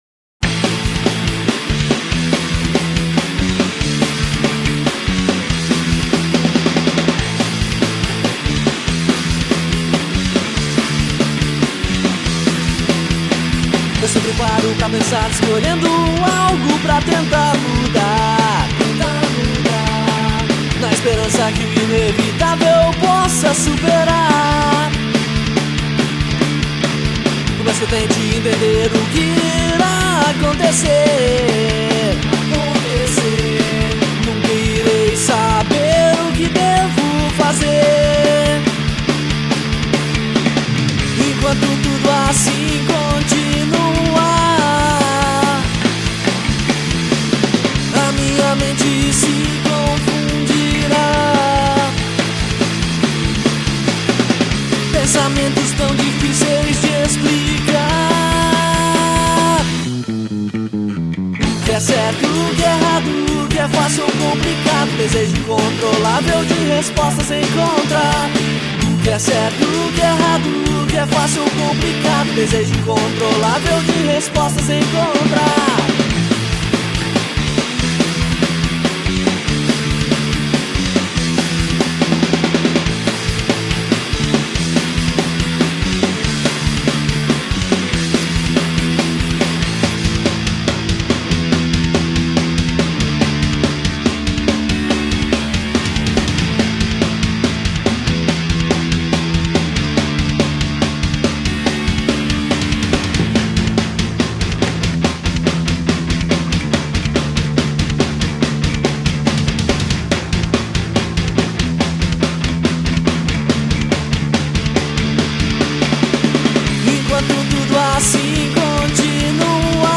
EstiloHardcore